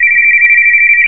Писклявый звук телефонного звонка